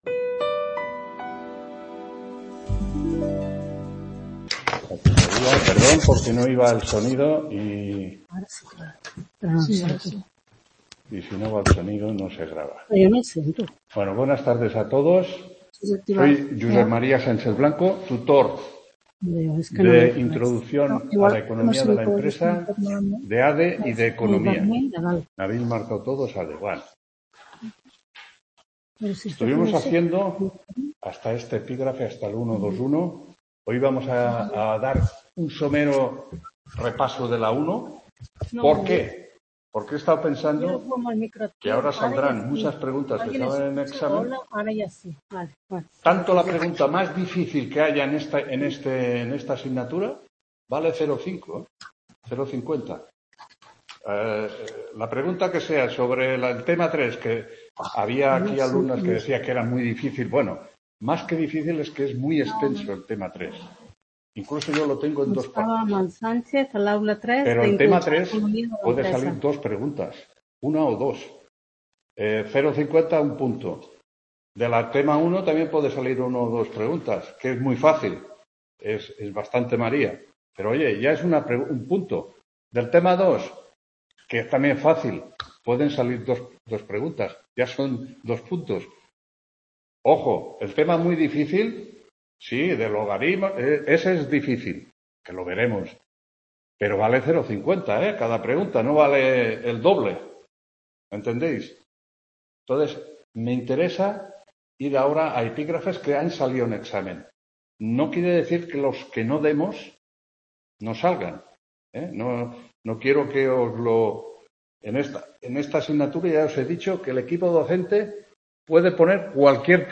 2ª TUTORÍA INTRODUCCIÓN A LA ECONOMÍA DE LA EMPRESA 24-10-23 (Nº 298)